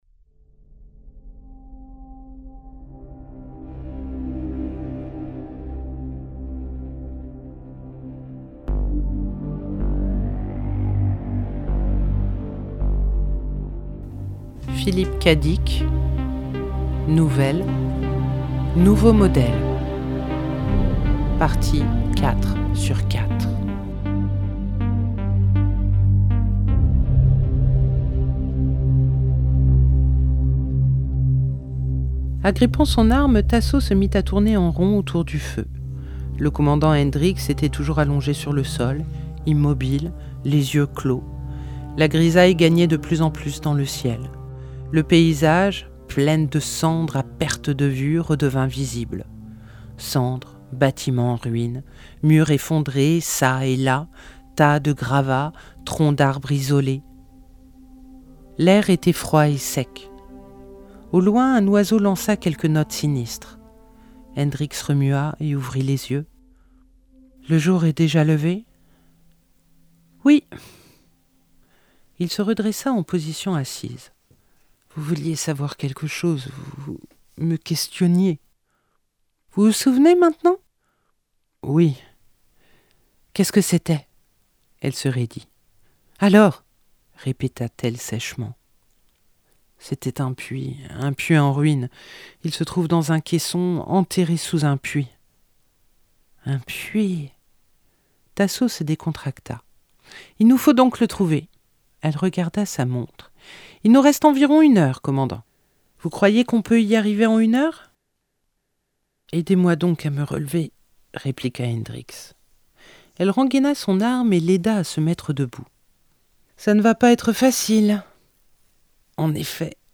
Nouvelle, partie 4/4 (26:34)